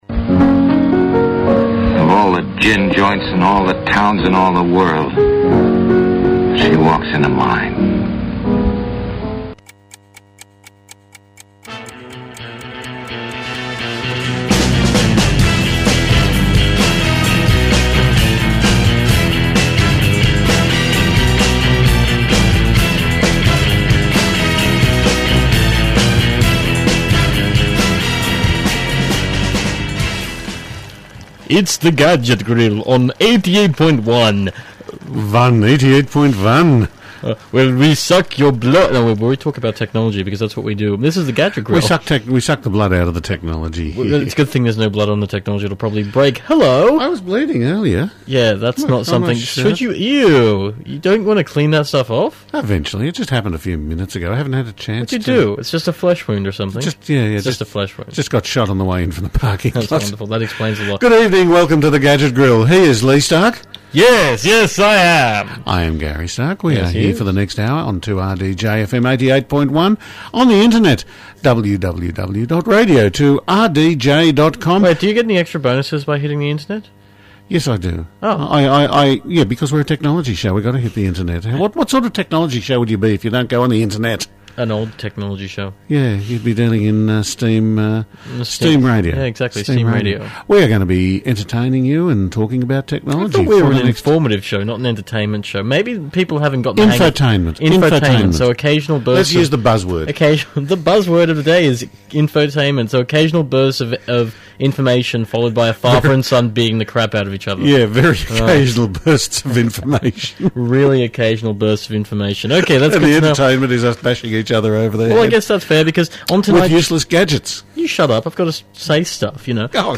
Plus we’ve got a big monitor in the studio… which was stupid because we could have just left it at home and talked about it. Regardless, we’ll be talking about the new Dell U2410 monitor which displays over one billion colours.